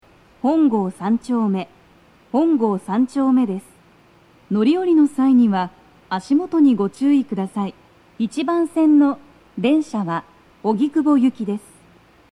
スピーカー種類 BOSE天井型
足元注意喚起放送が付帯されており、粘りが必要です。
1番線 荻窪・方南町方面 到着放送 【女声